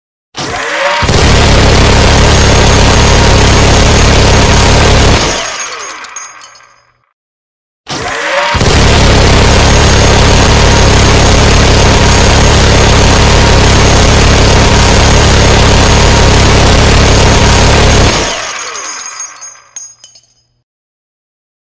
На этой странице собраны реалистичные звуки стрельбы из Минигана.
Две стрелковые очереди